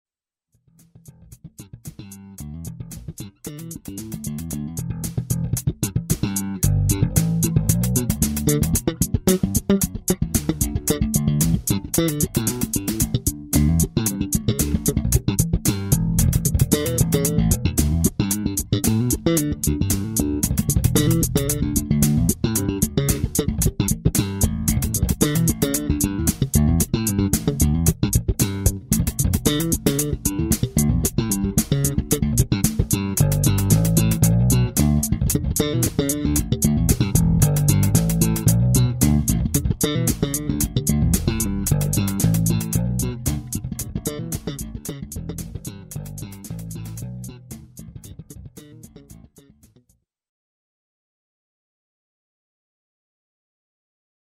ashdown_engineering_bass_amplification.mp3